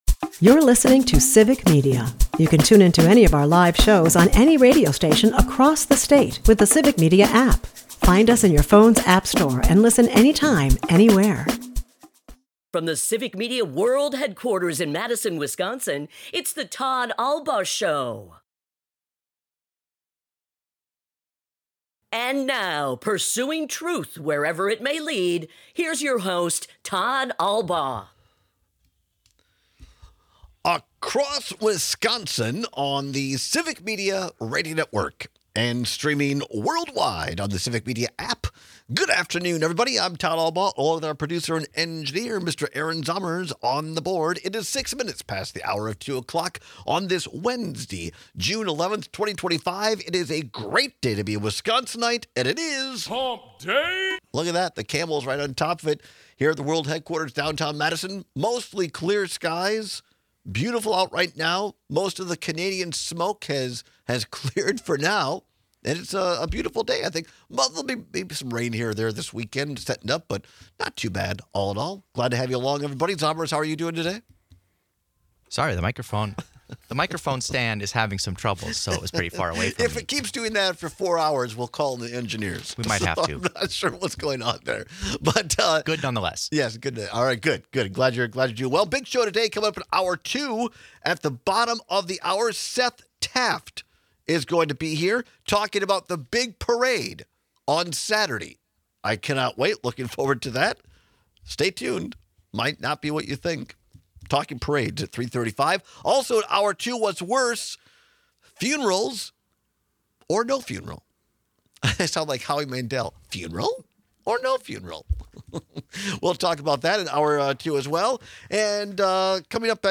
is a part of the Civic Media radio network and airs live Monday through Friday from 2-4 pm across Wisconsin.